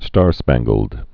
(stärspănggəld)